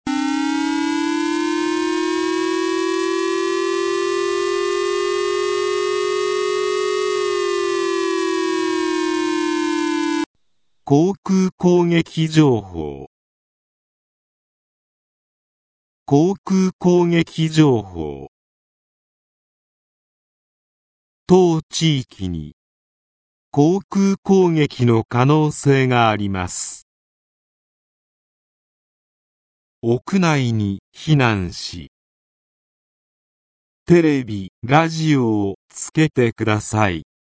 全国瞬時警報システム（J－ALERT）による放送例
武力攻撃等に関する情報の通報（例）